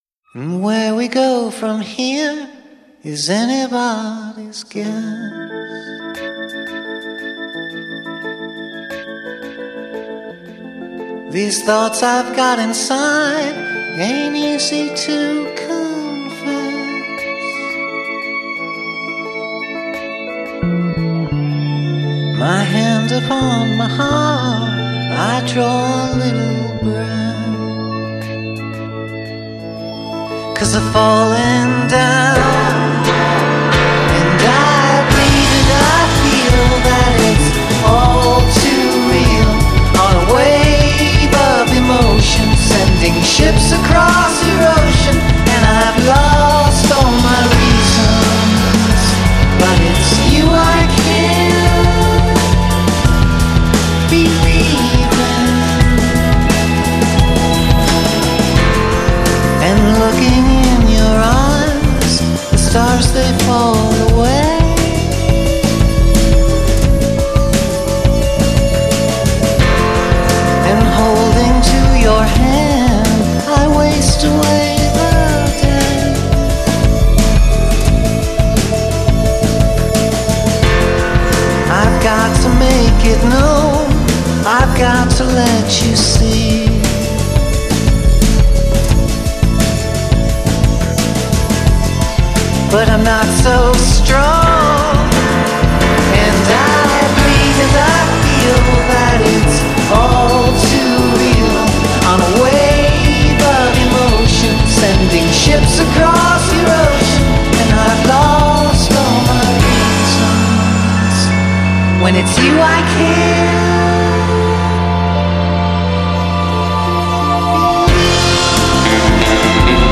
音乐风格：迷幻摇滚 乐队类型：朋克摇滚